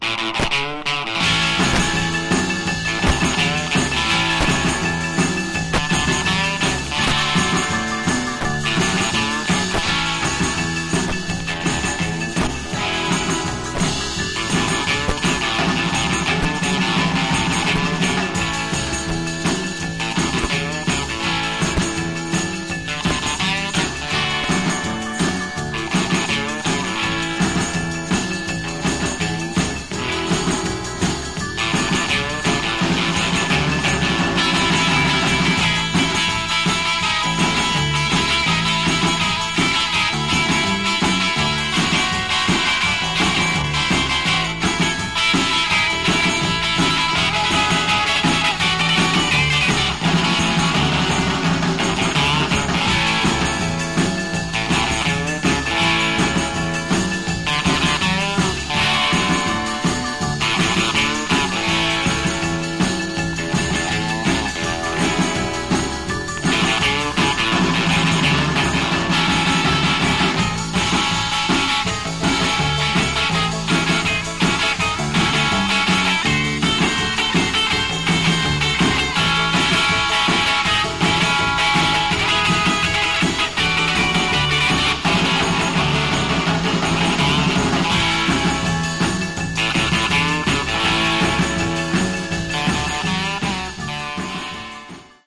Genre: Garage/Psych
With a guitar so fierce it started a whole new genre